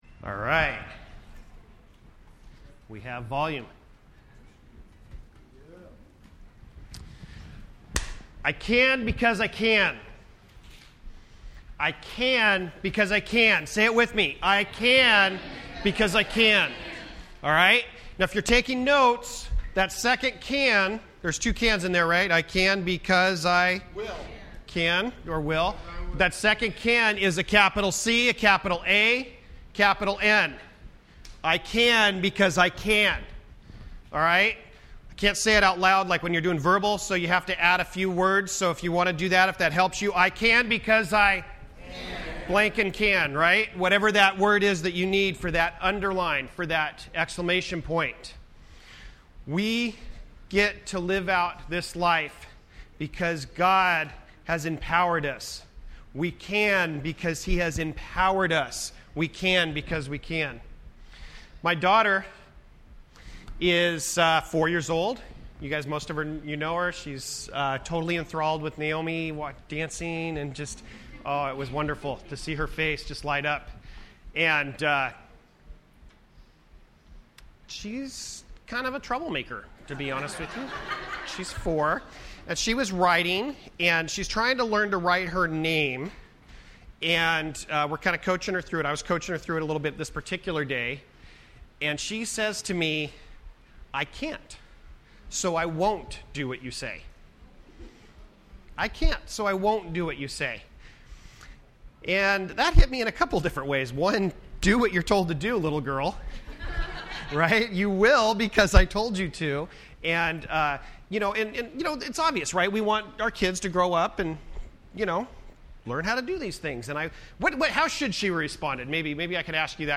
Series: FWC Sermons Service Type: Sunday Morning %todo_render% Related « The Core